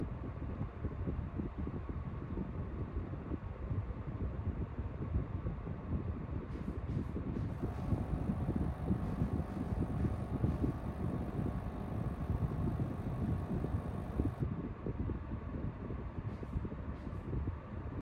L'Haori et l'un des deux Shorai (les deux plus éloignés) font un bruit intermittent, toutes les 2 minutes.
Bruit climatiseur Toshiba
Je pense que c'est la pompe de relevage qui se met en action.
Cette pompe se déclenche et aspire l'eau de la clim pour la rejeter plus loin.
bruit-climatiseur-toshiba.mp3